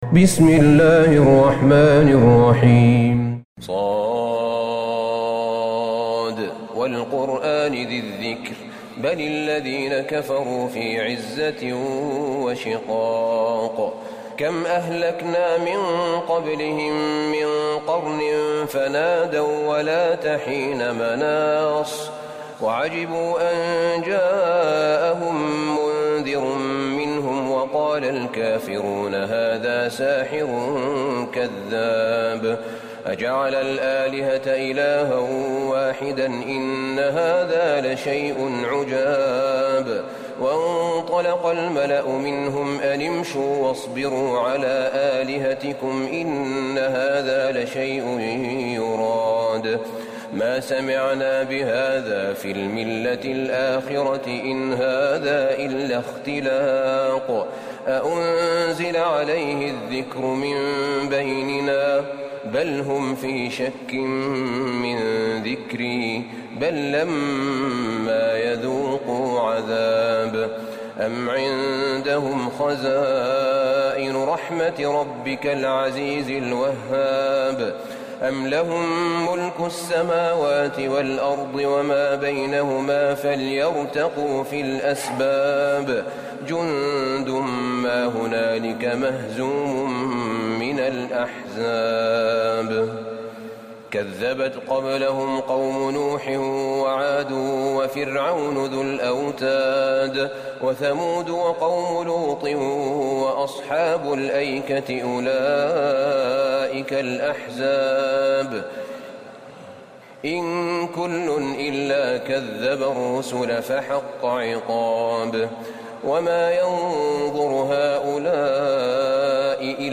سورة ص Surat Sad > مصحف الشيخ أحمد بن طالب بن حميد من الحرم النبوي > المصحف - تلاوات الحرمين